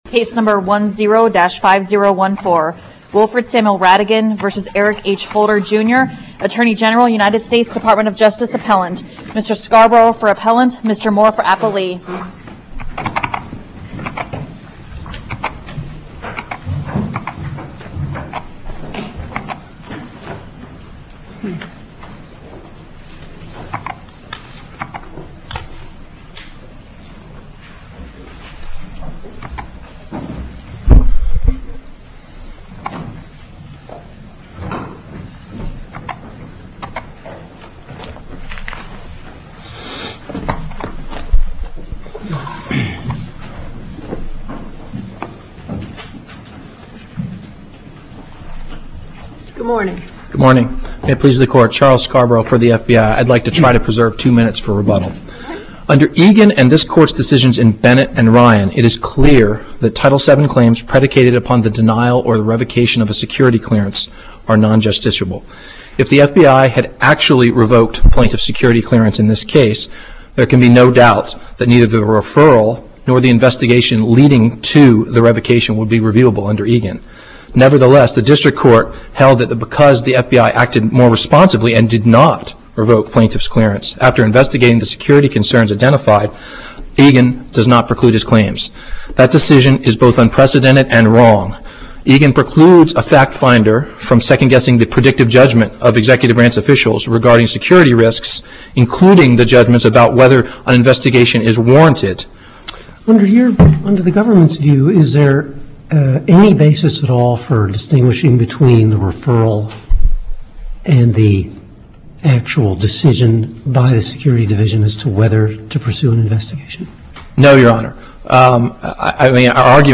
USCA-DC Oral Argument Recordings